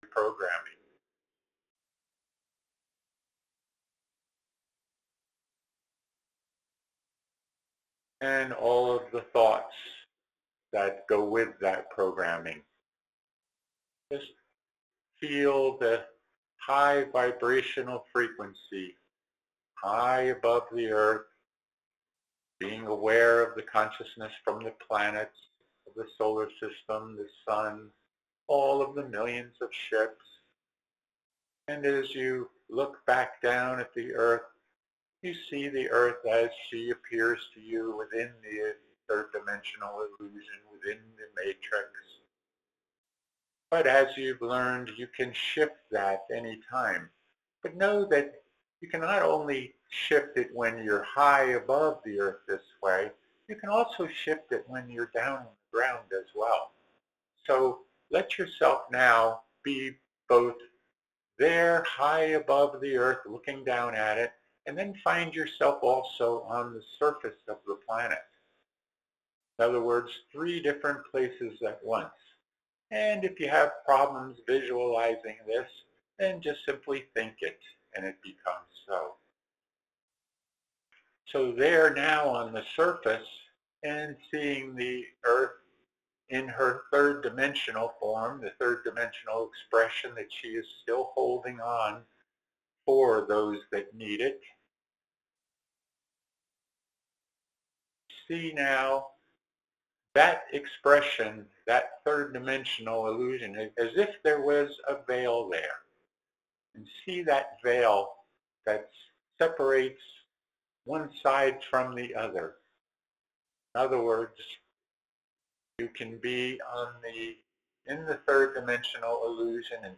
Join in group meditation with Lord Sananda.